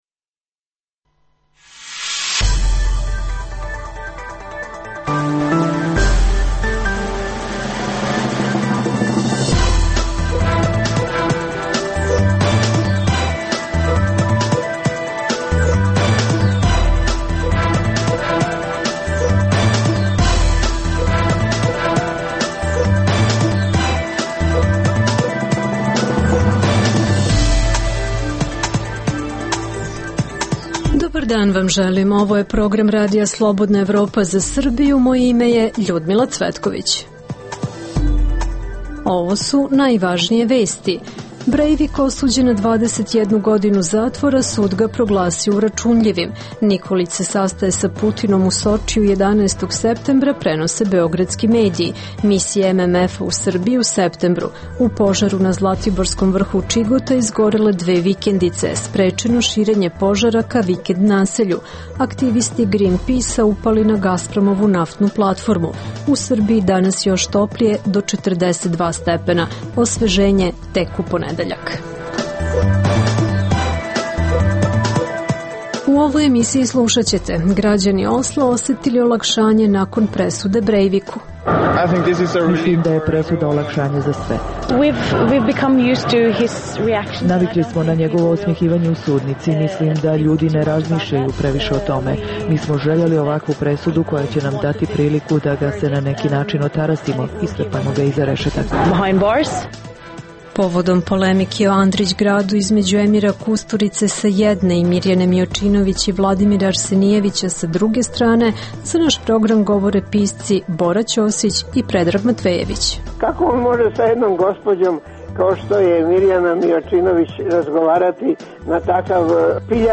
- Povodom polemike o Andrićgradu između Emira Kusturice sa jedne i Mirjane Miočinović i Vladimira Arsenijevica sa druge strane, za RSE govore pisci Bora Čosić i Predrag Matvejević.